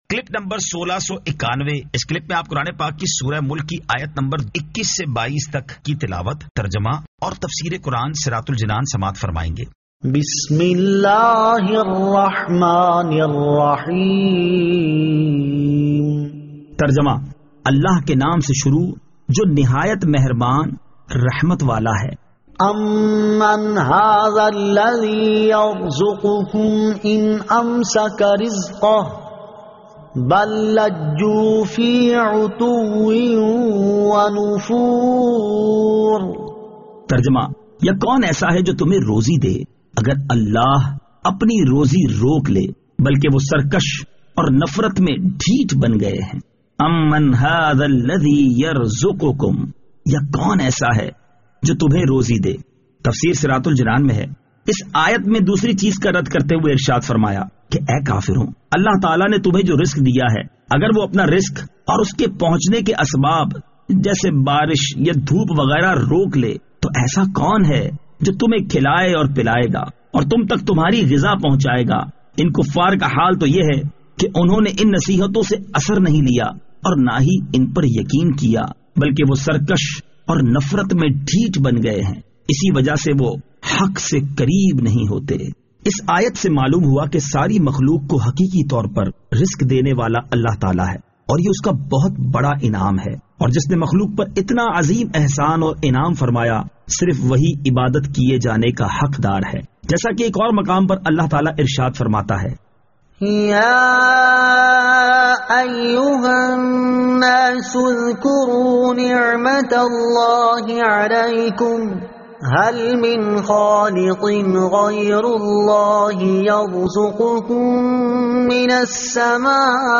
Surah Al-Mulk 21 To 22 Tilawat , Tarjama , Tafseer